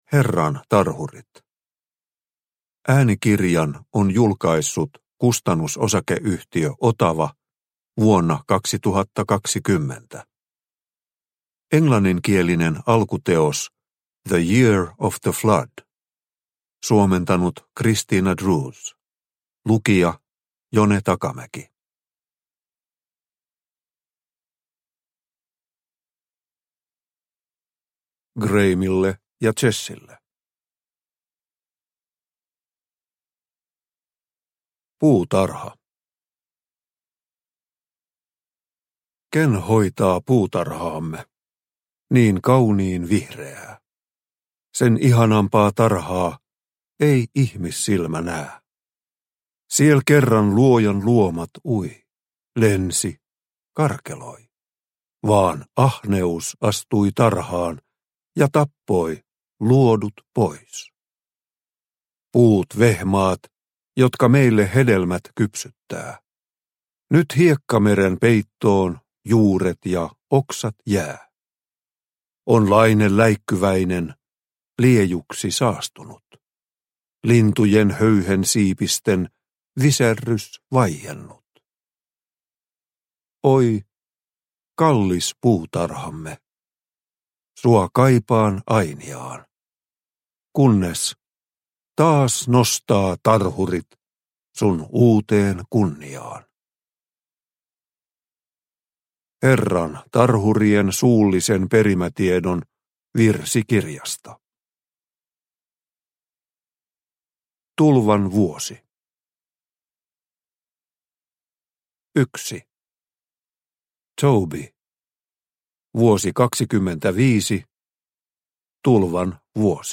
Herran tarhurit – Ljudbok – Laddas ner